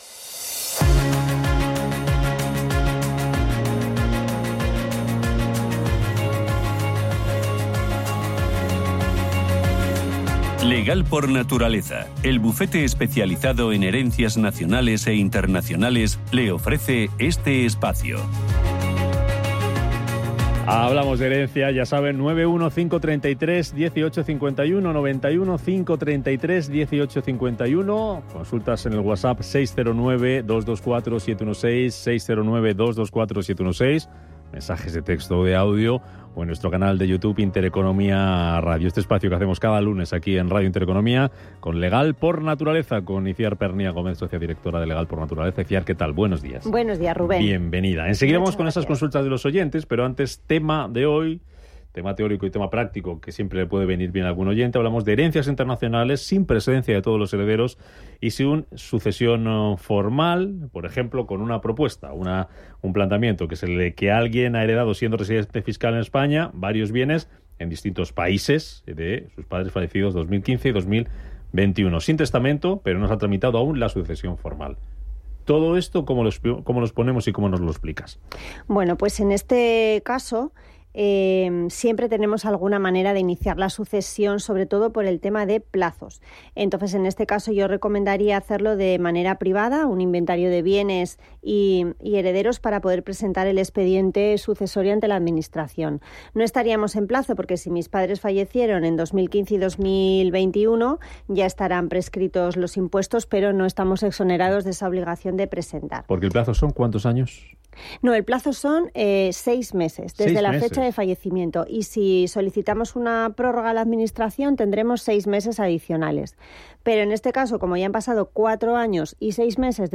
Hoy en el consultorio de herencias de Radio Intereconomía, hablamos de herencias internacionales, planteando que alguien ha heredado siendo residente fiscal en España, varios bienes en distintos países con padres fallecidos en 2015 y 2021, sin testamento pero sin tramitar aún la sucesión formal.